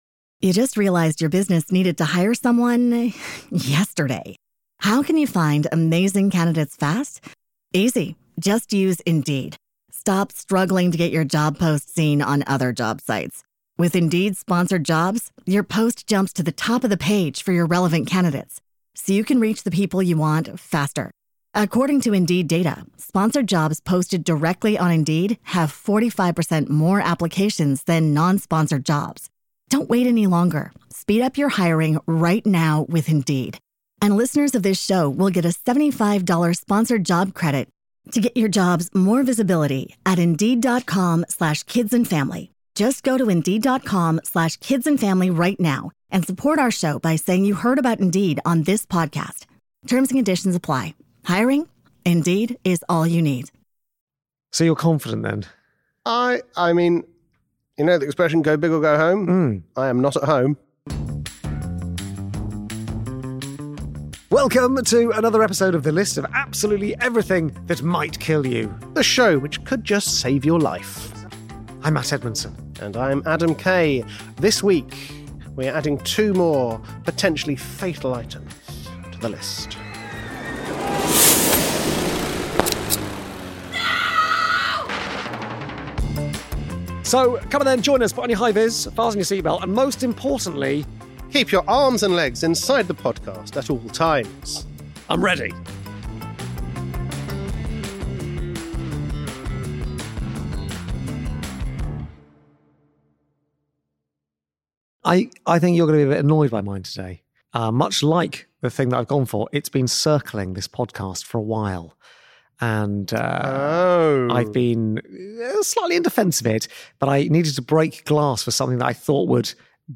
Presented by Matt Edmondson & Adam Kay